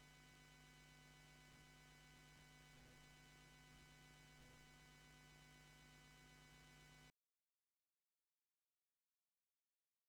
Вот, перемкнул аналоговый вход с выходом, записал. Буфер 64 семпла. Вложения AIO IN (+24db).wav AIO IN (+24db).wav 1,4 MB · Просмотры: 468